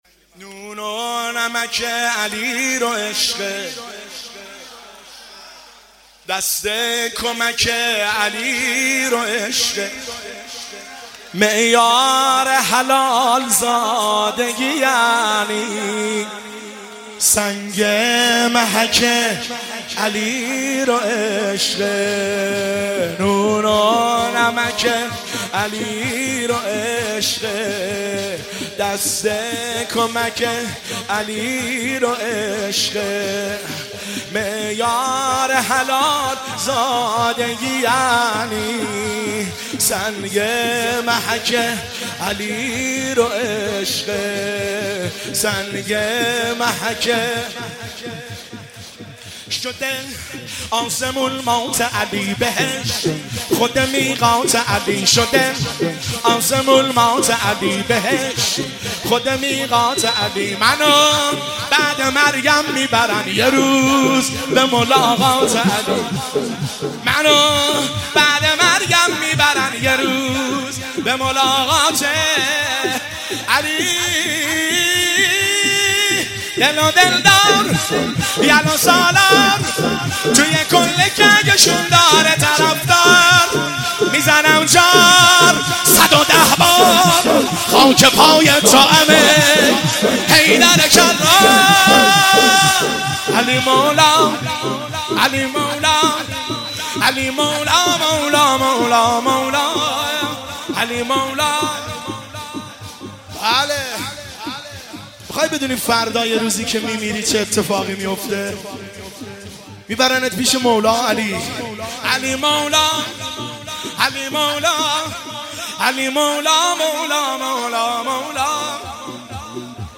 شور شب 23 رمضان المبارک 1403
هیئت بین الحرمین طهران